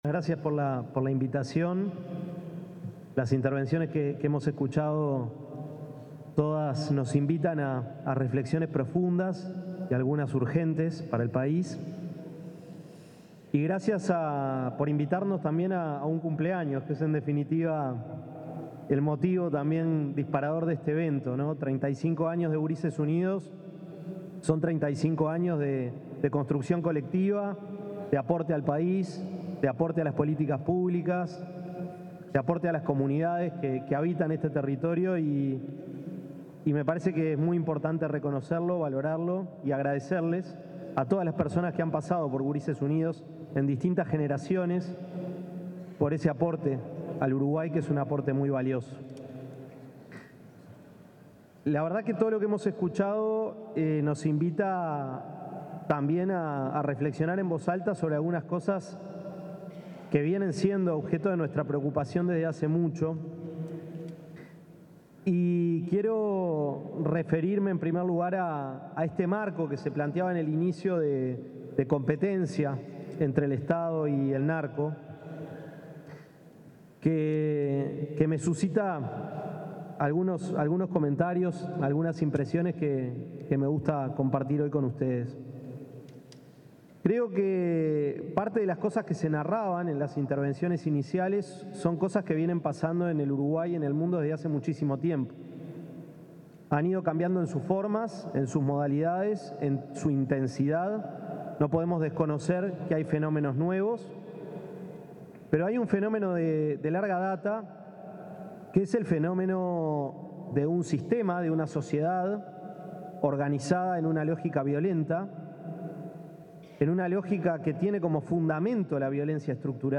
Palabras del ministro de Desarrollo Social, Gonzalo Civila
Palabras del ministro de Desarrollo Social, Gonzalo Civila 13/06/2025 Compartir Facebook X Copiar enlace WhatsApp LinkedIn Durante el conversatorio Infancias, Adolescencias y Crimen Organizado, convocado por la organización no gubernamental Gurises Unidos, se expresó el ministro de Desarrollo Social, Gonzalo Civila.